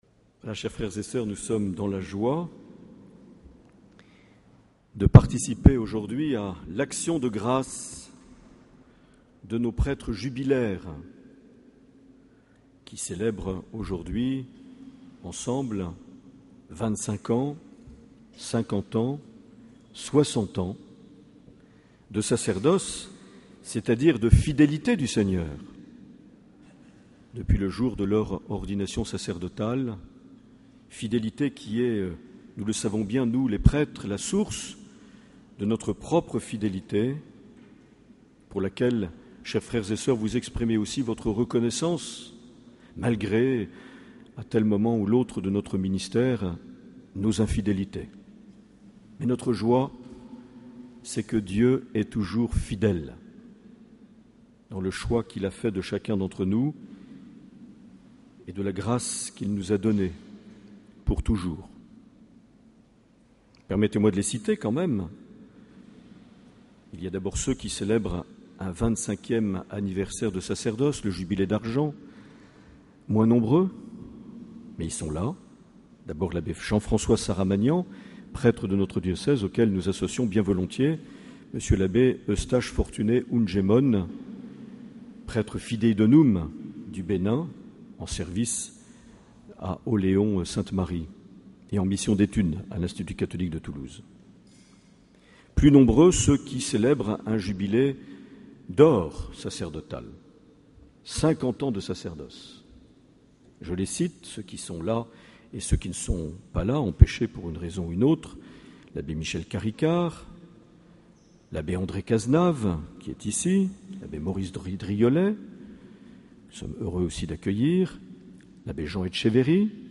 25 juin 2014 - Cathédrale de Bayonne - Messe avec les prêtres jubilaires et admission de
Accueil \ Emissions \ Vie de l’Eglise \ Evêque \ Les Homélies \ 25 juin 2014 - Cathédrale de Bayonne - Messe avec les prêtres jubilaires et (...)
Une émission présentée par Monseigneur Marc Aillet